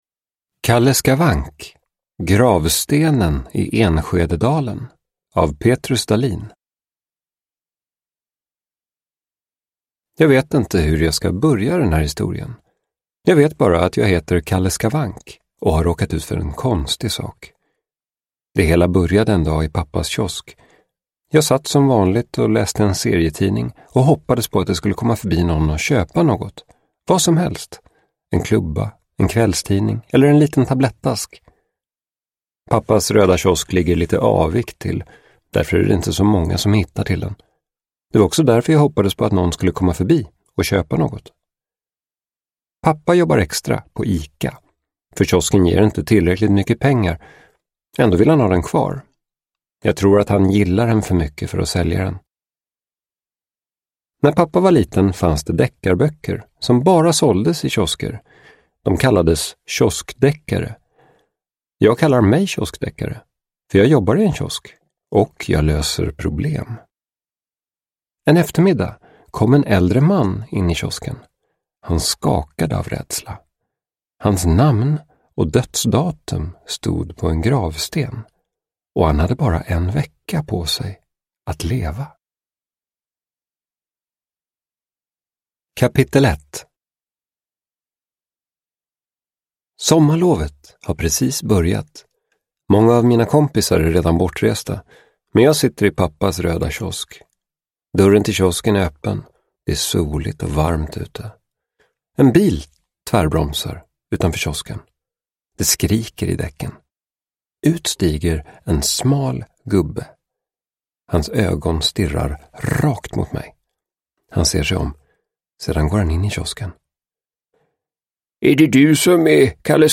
Gravstenen i Enskededalen – Ljudbok – Laddas ner